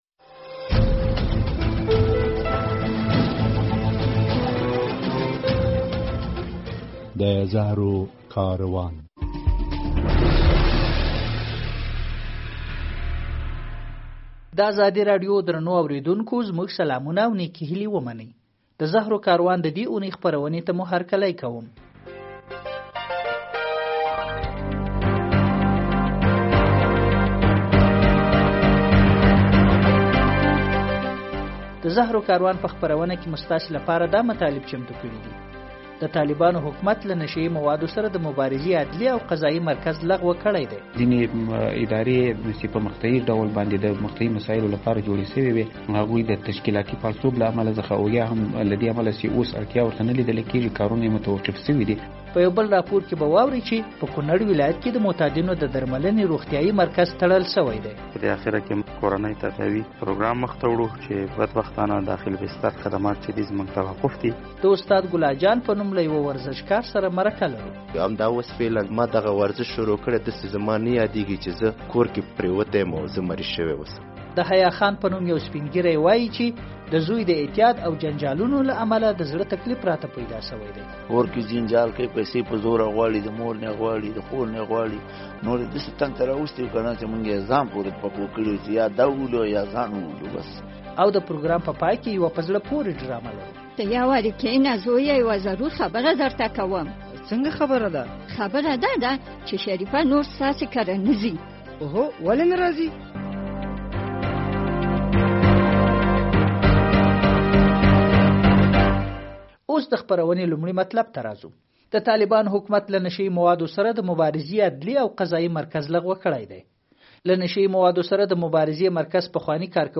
د يوه معتاد خاطره او په پای کې ډرامه هم اورېدلای شئ.